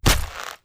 Melee Weapon Attack 22.wav